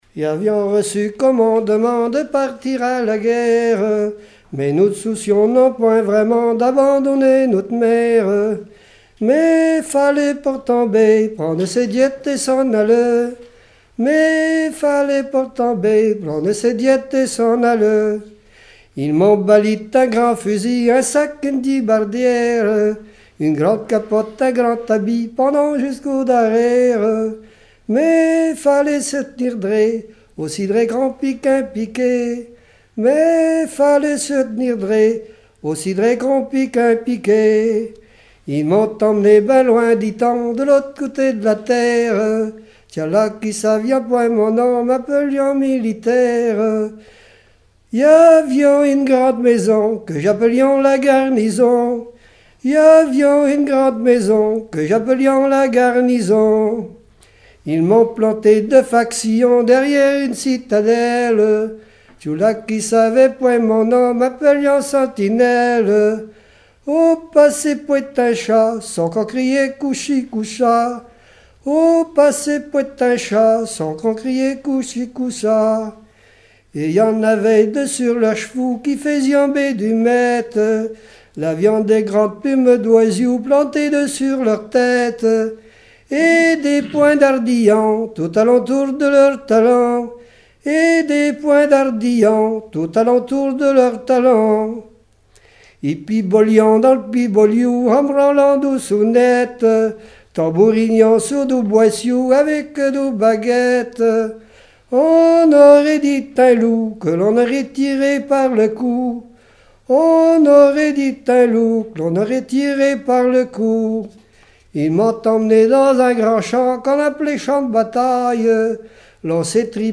Genre strophique
collectif de chanteurs du canton
Pièce musicale inédite